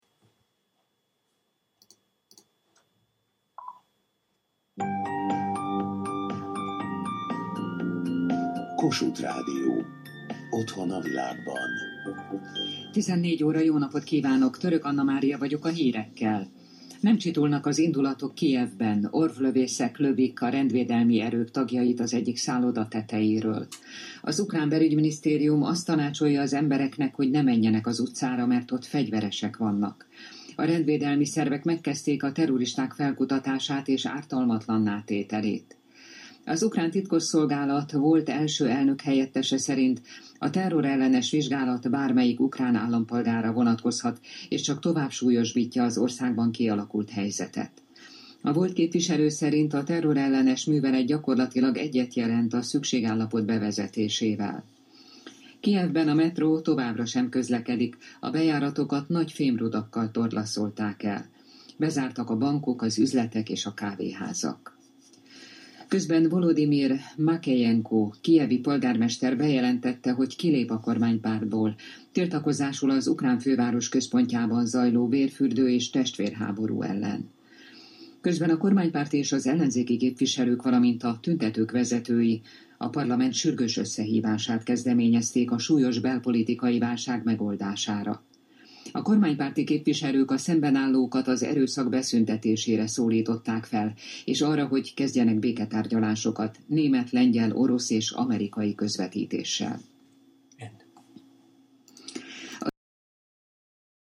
On February 20, the bloodiest day of clashes between anti-government Euromaidan protestors and police in Kiev, Ukraine, Hungary’s state-run Kossuth Radio broadcast the following report in Hungarian about the demonstrations during the station’s 2:00 p.m. news (source in Hungarian at 14:00):